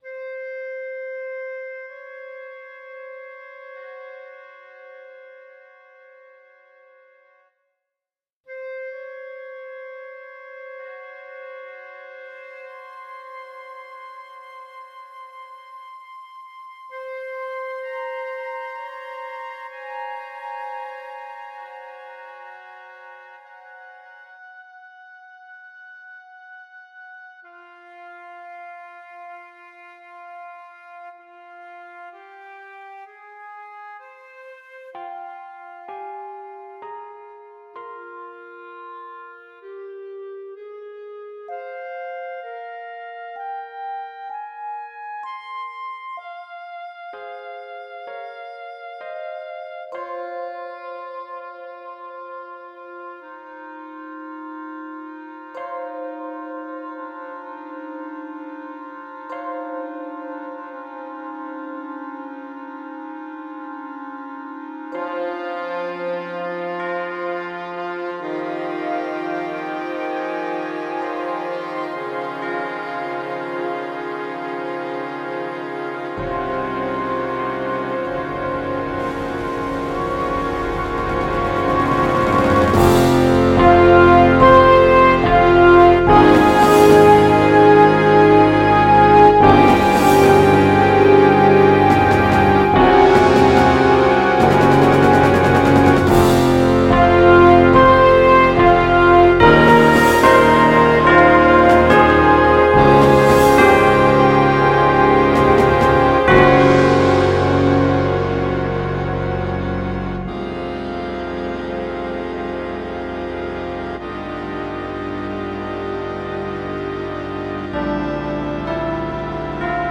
Dischord - Orchestral and Large Ensemble - Young Composers Music Forum
Please do give feedback if you can on what you think about this piece ***WARNING: Playback is very loud, so ADJUST volume if needed***